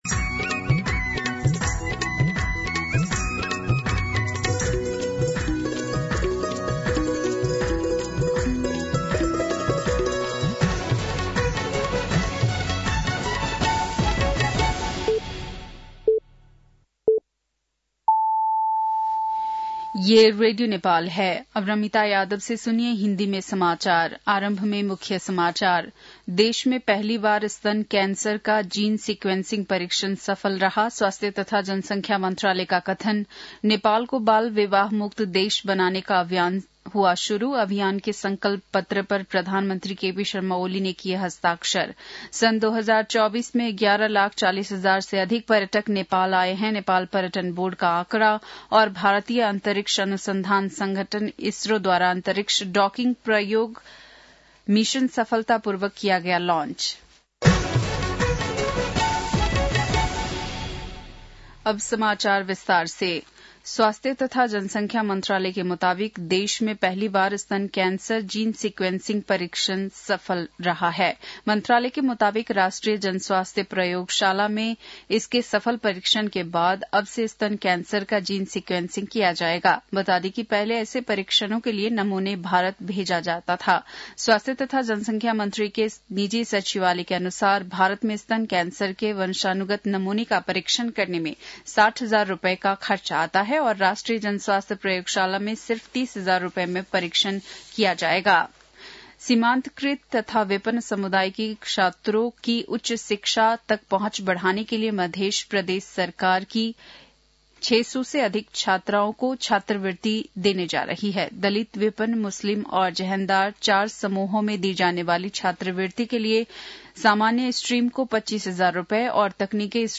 बेलुकी १० बजेको हिन्दी समाचार : १७ पुष , २०८१
10-PM-Hindi-News-9-16.mp3